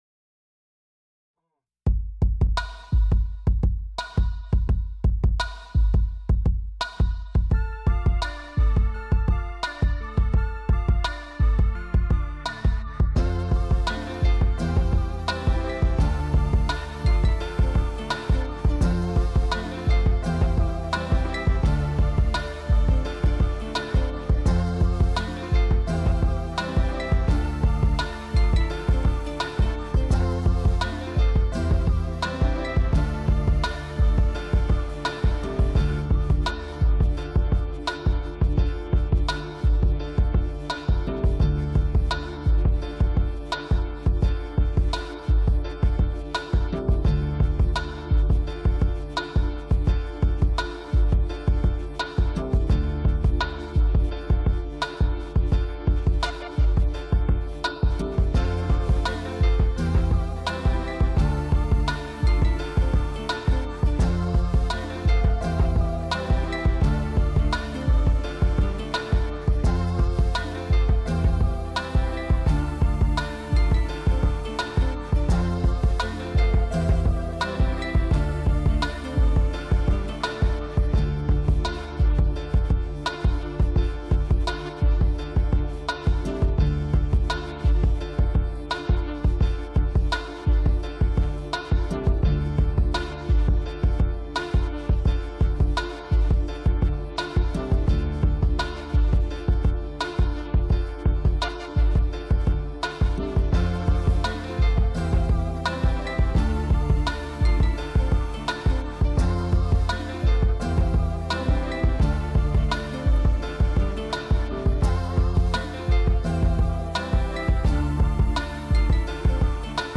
Hip hop
Hiphop beats